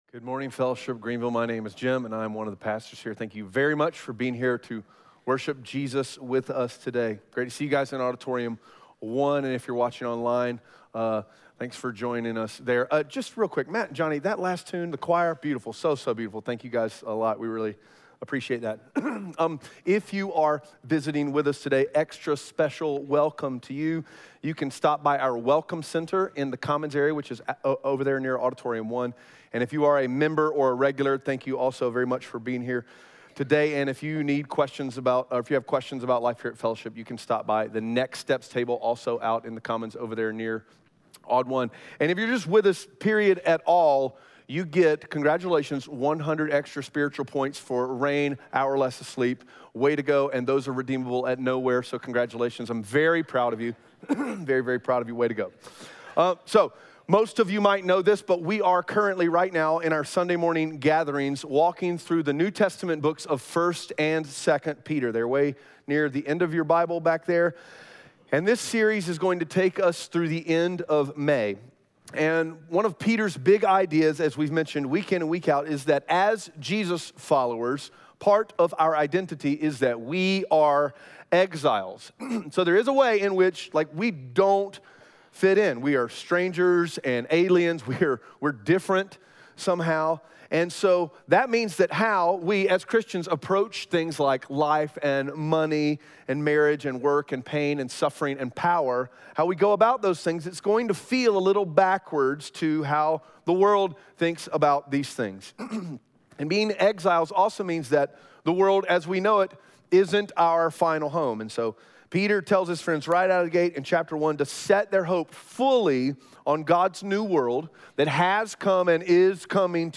1 Peter 4:1-11 Audio Sermon Notes (PDF) Ask a Question Yes, we are exiles.